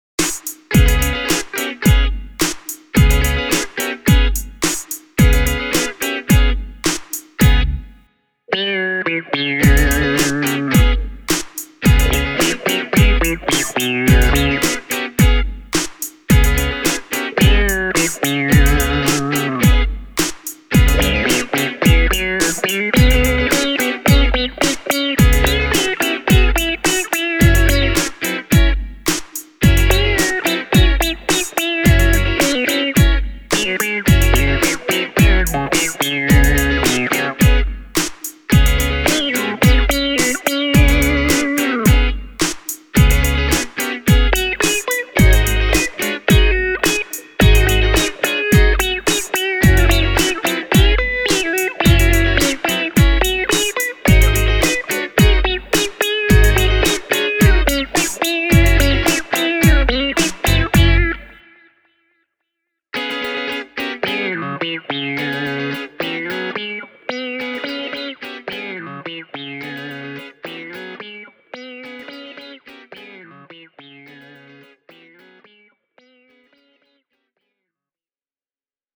The Seymour Duncan P-90s give you a wide range of different tones on the Liekki, from jazzy warmth all the way to gritty Rock.